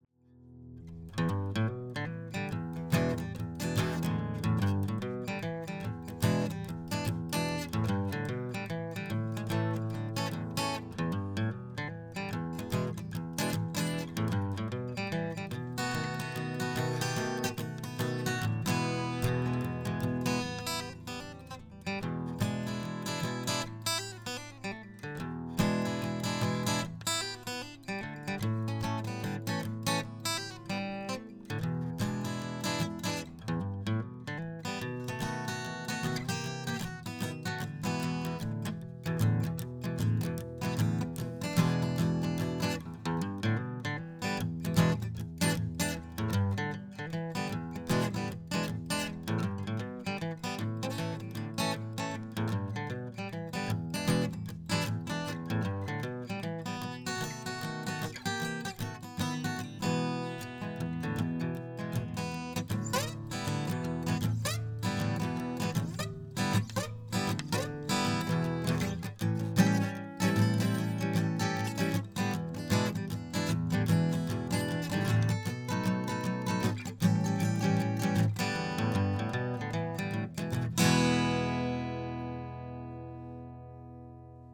Guitar Mic Comparisons
The next clip is the same piece with a SM 57 mic placed between the neck and the opening on the front of the guitar. It is placed half the diameter of the opening away from the strings.
There is very little  perceptible difference to hear.